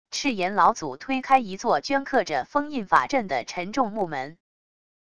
赤炎老祖推开一座镌刻着封印法阵的沉重木门wav音频